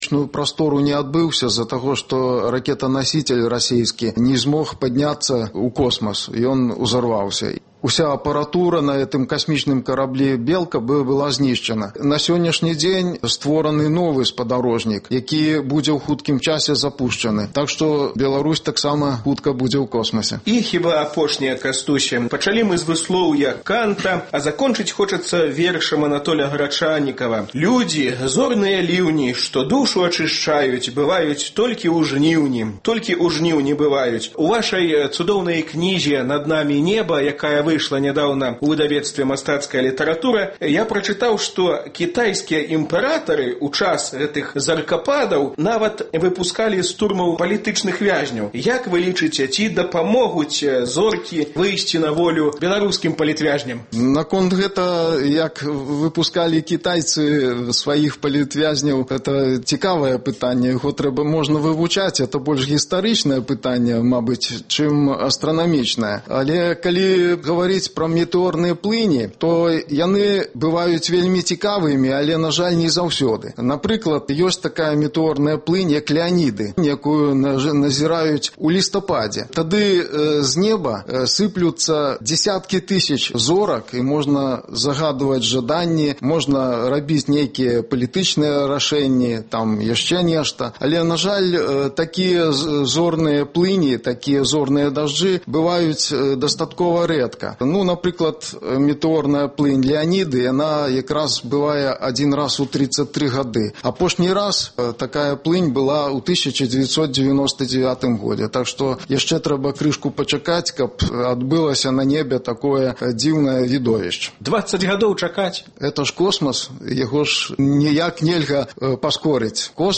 Гутаркі без цэнзуры зь дзеячамі культуры й навукі